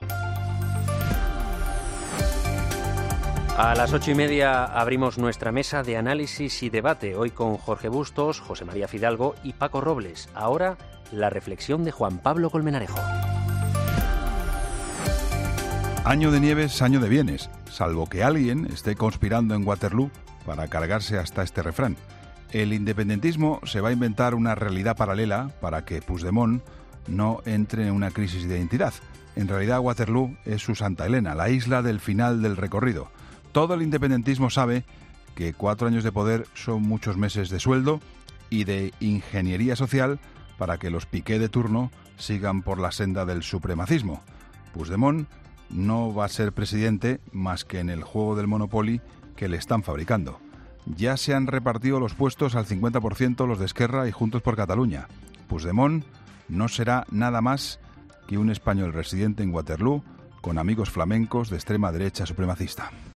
AUDIO: Escucha el comentario del director de 'La Linterna', Juan Pablo Colmenarejo, en 'Herrera en COPE'